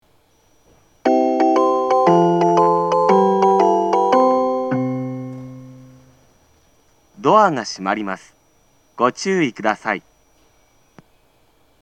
■厚木駅　ホームの様子（社家方から撮影）
発車メロディー
一度扱えばフルコーラス鳴ります。
放送の音量は普通です。